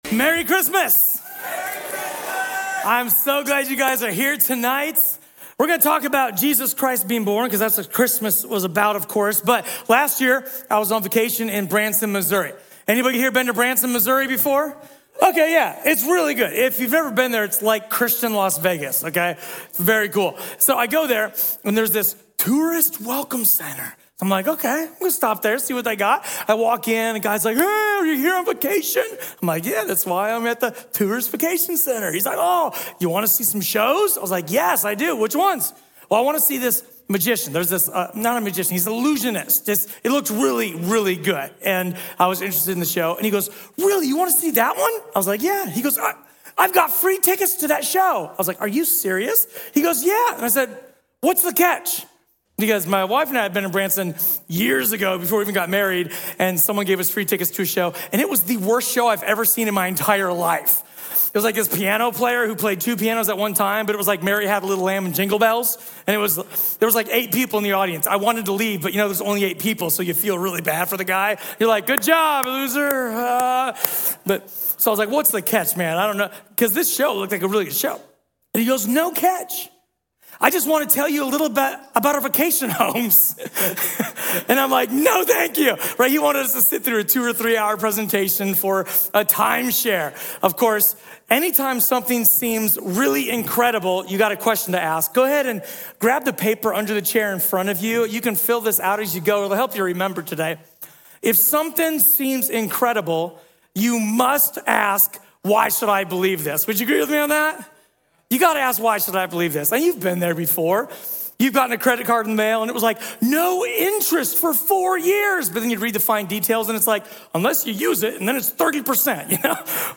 A sermon from the series "Christmas."